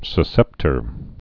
(sə-sĕptər)